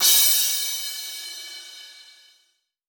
Crashes & Cymbals
Str_Crsh2.wav